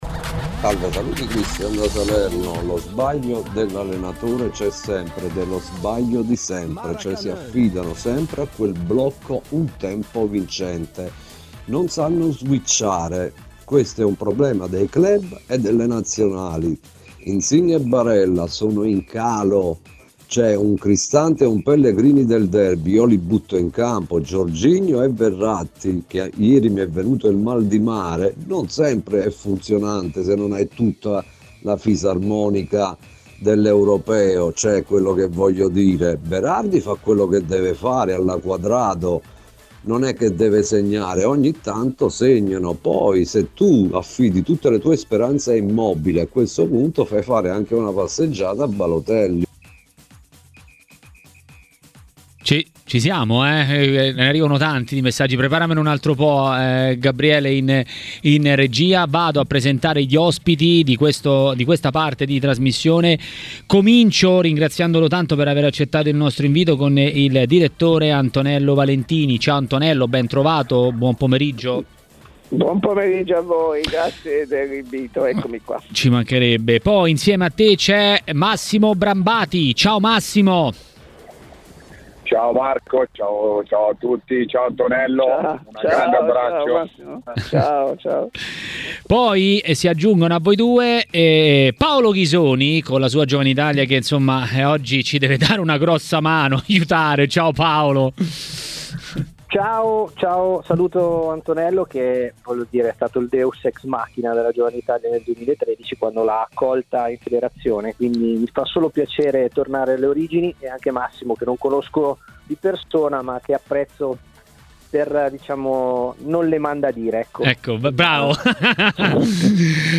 a Maracanà, nel pomeriggio di TMW Radio